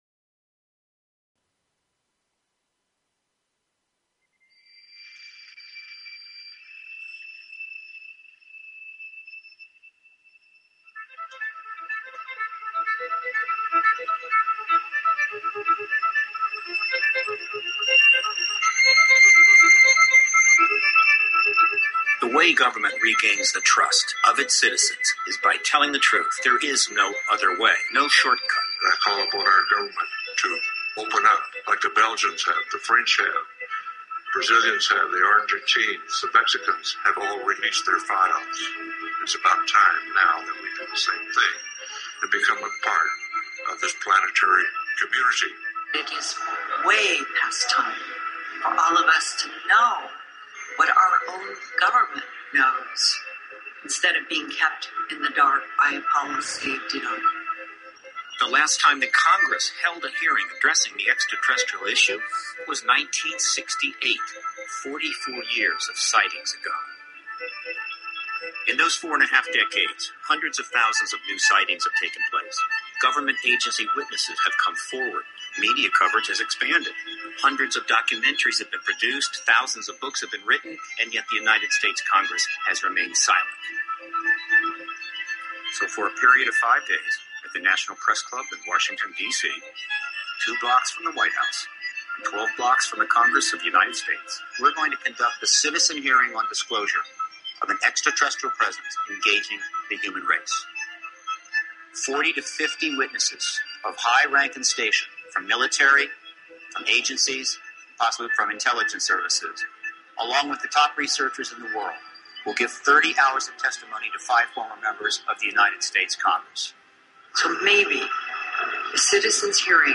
Talk Show Episode, Audio Podcast, Galactic_Roundtable and Courtesy of BBS Radio on , show guests , about , categorized as